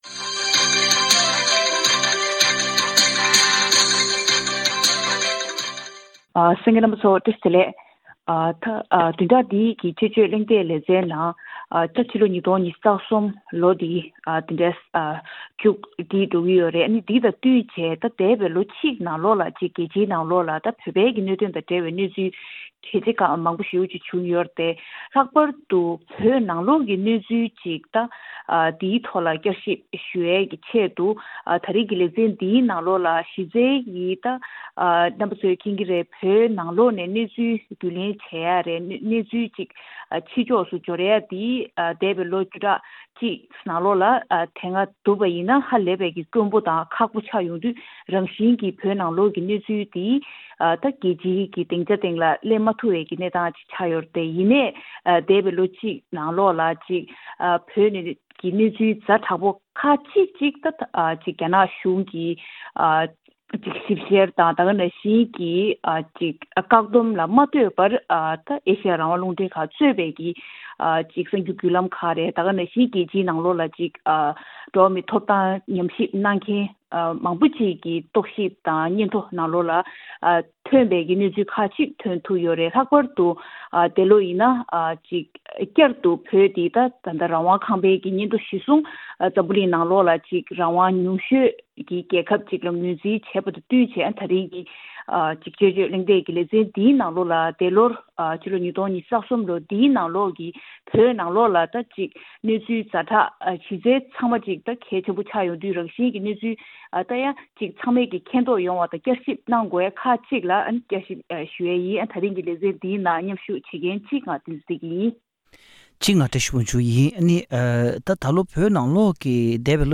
གླེང་མོལ་ཞུས་པར་གསན་རོགས་གནང་།།